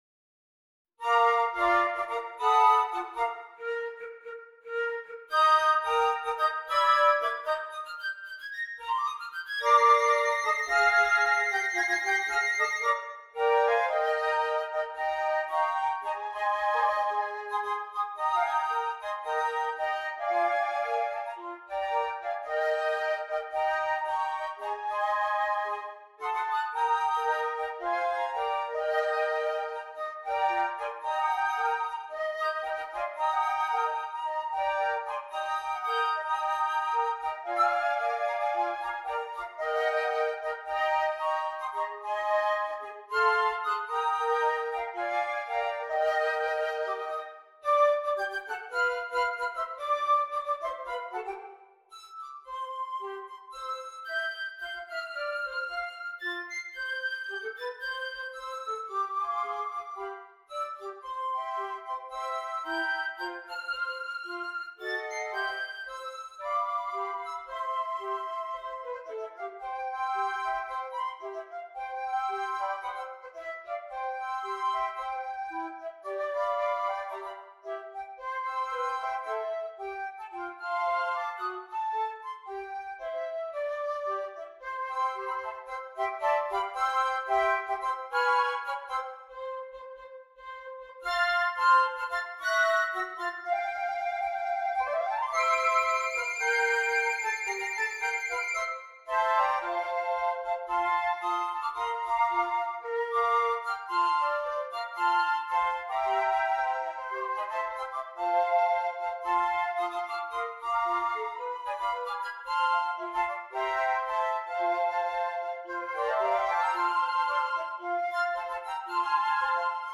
5 Flutes